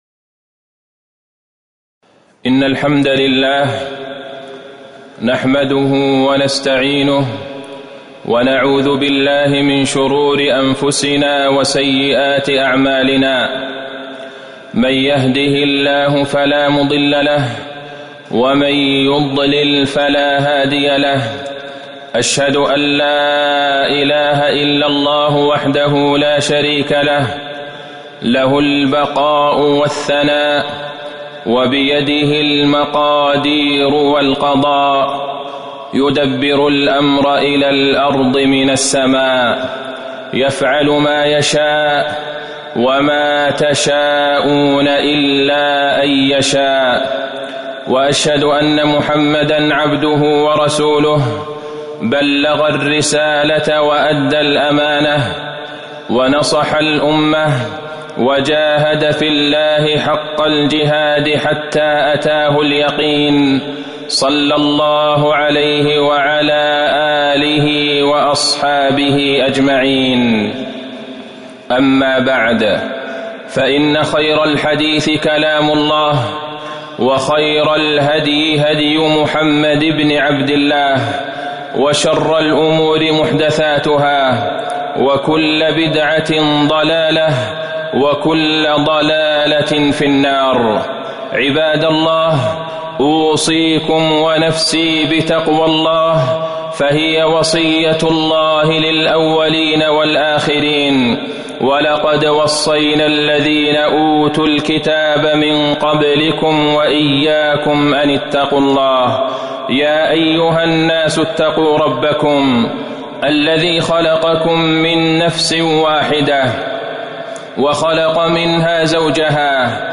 تاريخ النشر ١٥ رجب ١٤٤٠ هـ المكان: المسجد النبوي الشيخ: فضيلة الشيخ د. عبدالله بن عبدالرحمن البعيجان فضيلة الشيخ د. عبدالله بن عبدالرحمن البعيجان نعمة الأمن والأمان